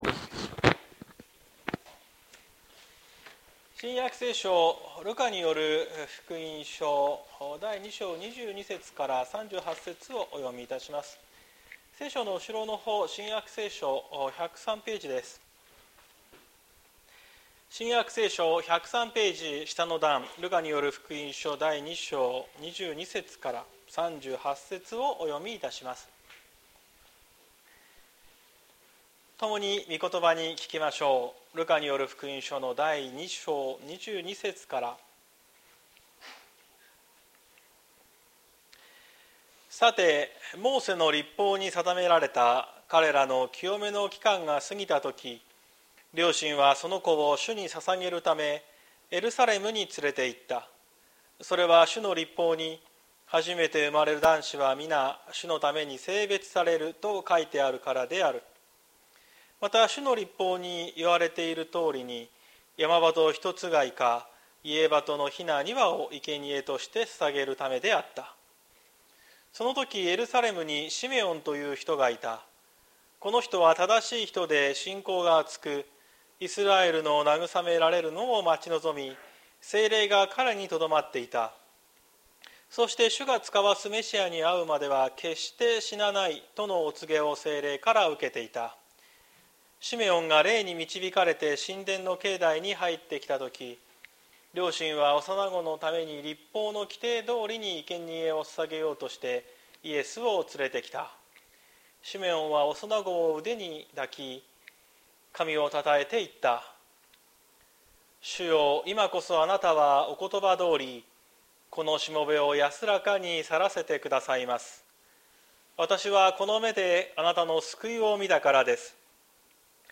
2024年12月29日朝の礼拝「シメオンの歌」綱島教会
説教アーカイブ。